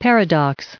added pronounciation and merriam webster audio
1116_paradox.ogg